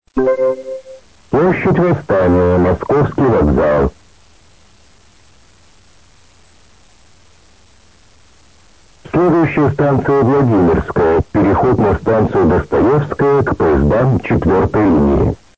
Скажу сразу, что интонации всё-таки заставили его изменить на более энергичные, чем было прежде. Голос узнаётся, но окраска вся пропала.
Несильно зажат динамический диапазон, но очень сильно заужена полоса - плоский звук как из динамика мобильника.